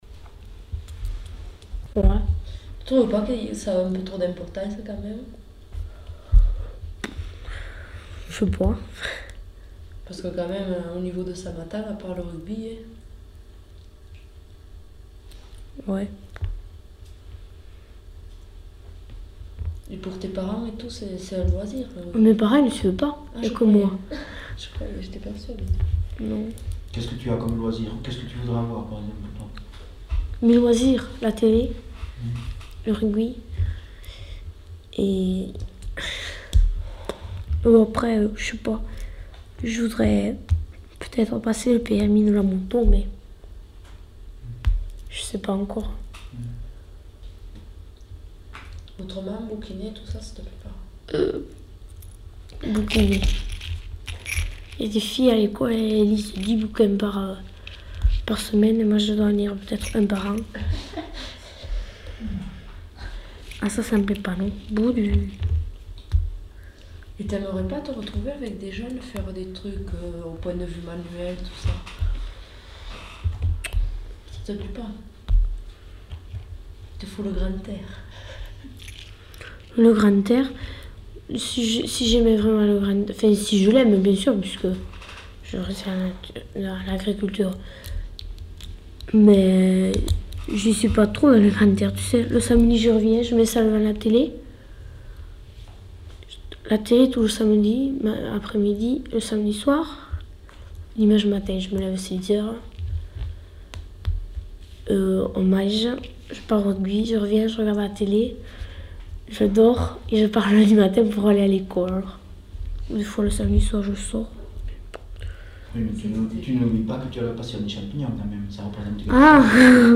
Aire culturelle : Savès
Lieu : Sauveterre
Genre : récit de vie